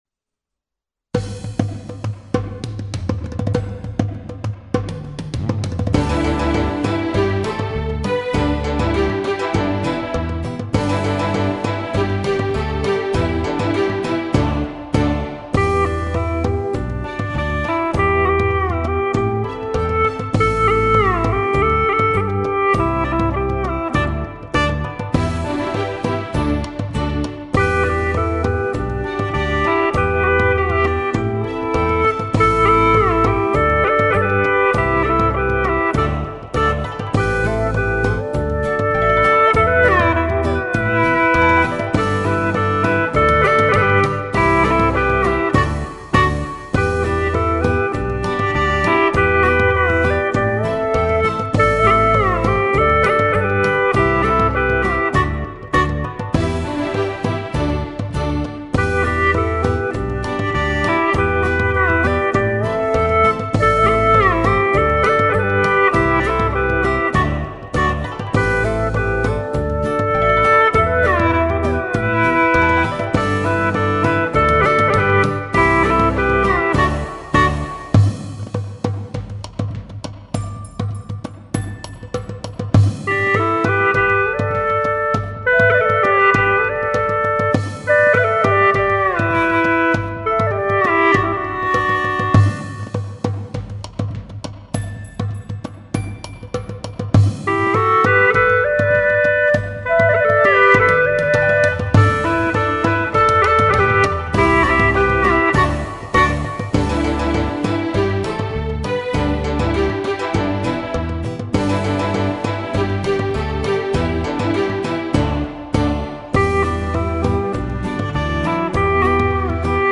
调式 : G 曲类 : 民族